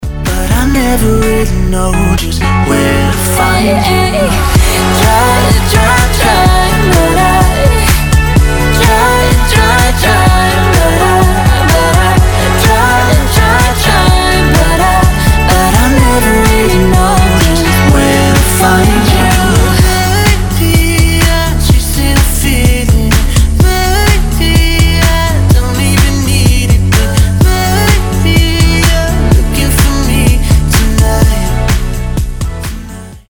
• Качество: 320, Stereo
поп
dance
дуэт
женский и мужской вокал